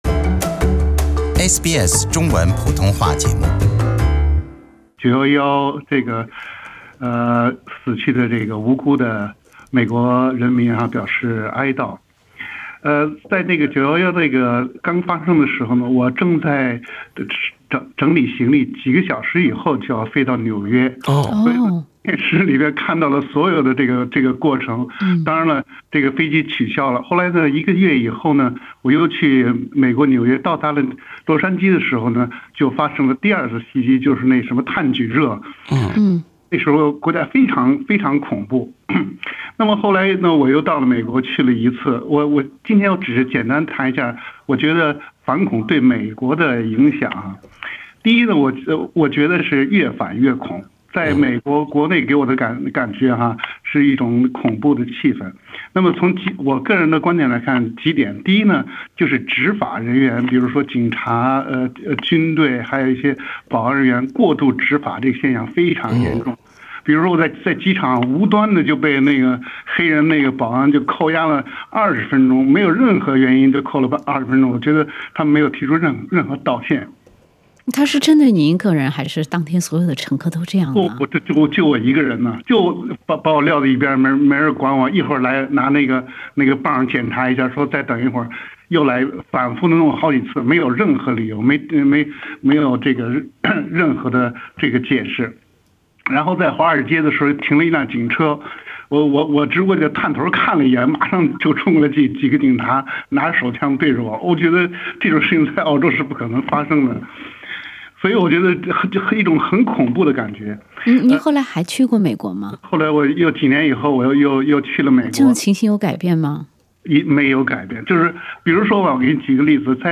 惊弓之鸟的美国社会 - 后911时代旅美见闻者口述